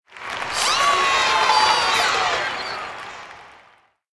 KART_Applause_2.ogg